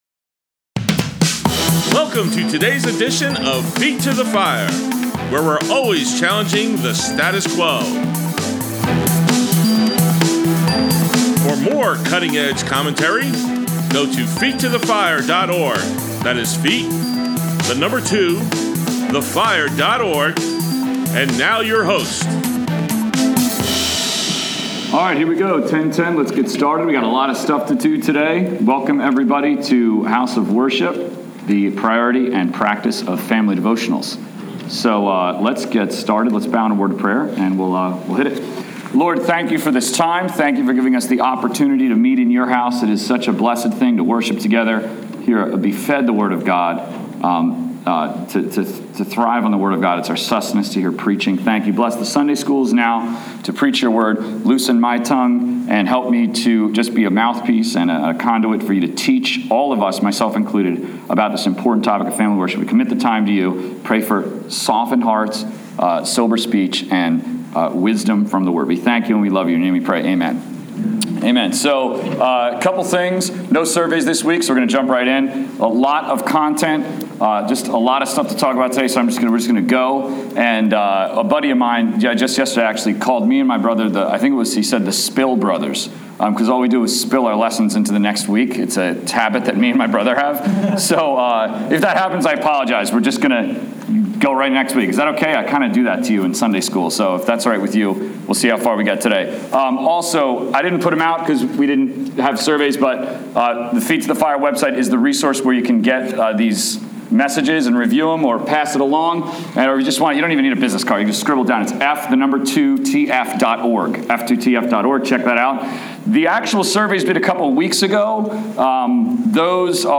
Grace Bible Church, Adult Sunday School, 1/31/16